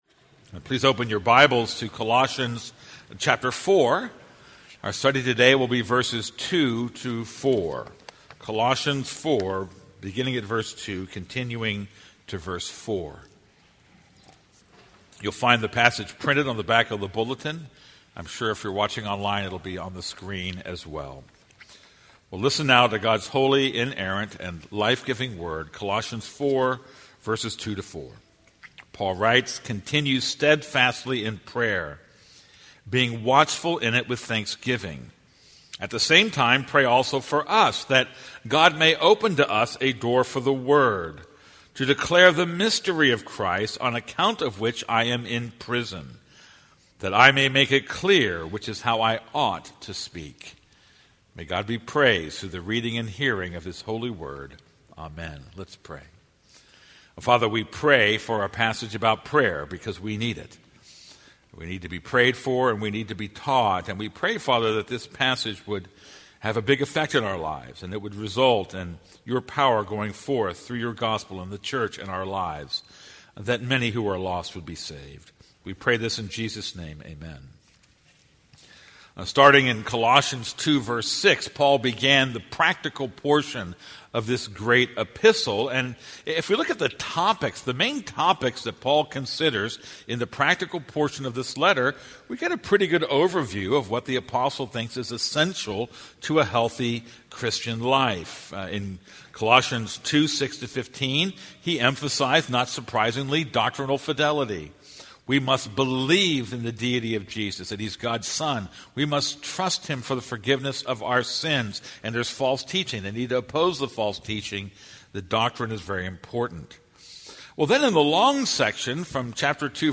This is a sermon on Colossians 4:2-4.